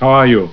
Kindergarten Cop Movie Sound Bites